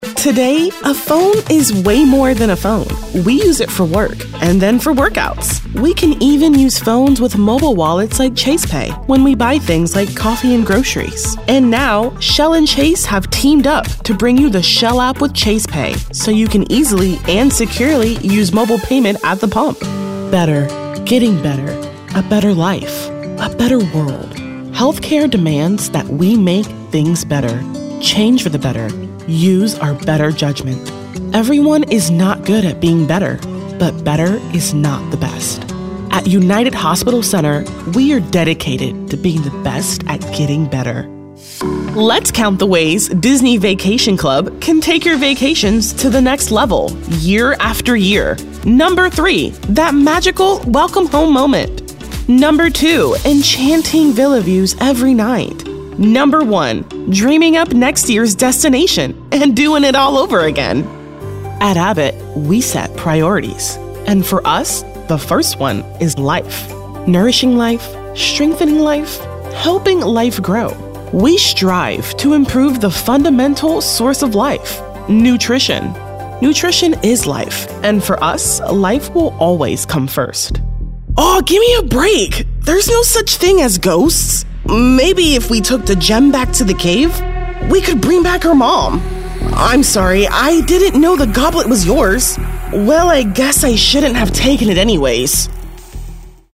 African American, animated, announcer, confident, cool, friendly, girl-next-door, millennial, perky, real, smooth, upbeat